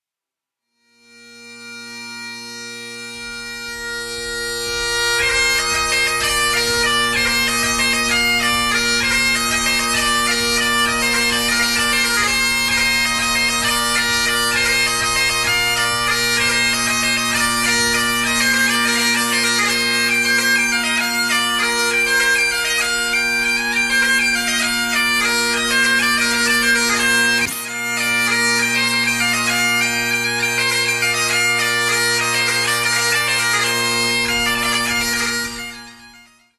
gaita.mp3